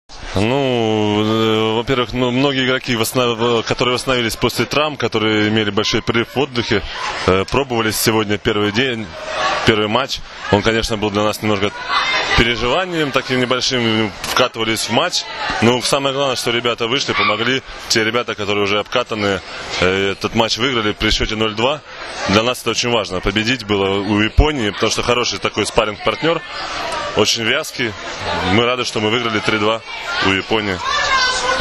IZJAVA TARASA HTEIJA